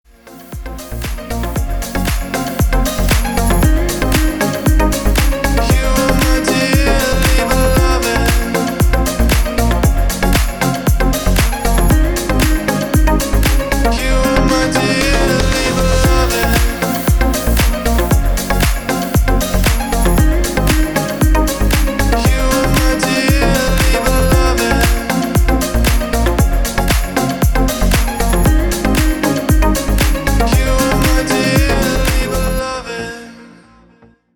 • Качество: 320, Stereo
мужской голос
Electronic
спокойные
Стиль: deep house